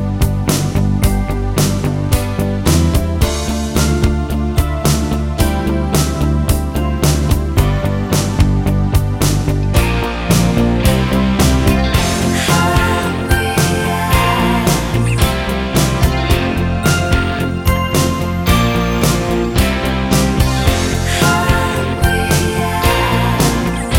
Five Semitones Down Soft Rock 4:09 Buy £1.50